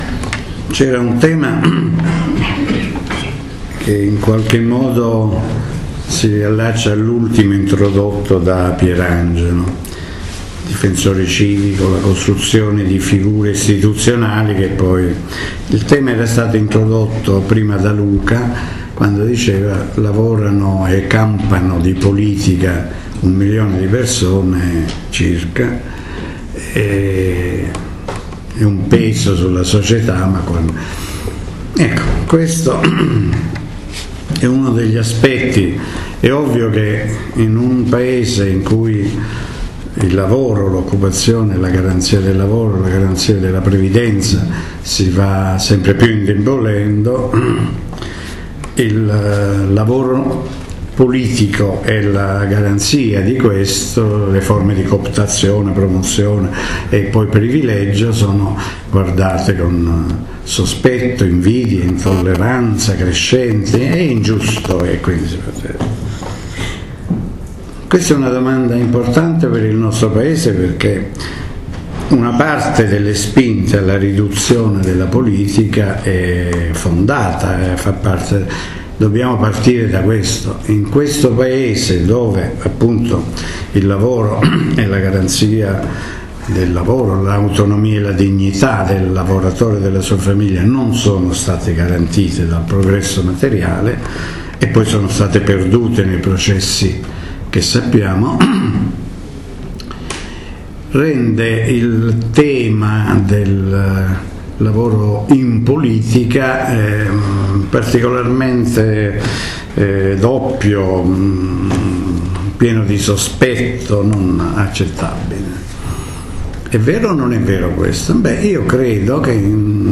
al Corso di Formazione alla politica 2013-2014 dei Circoli Dossetti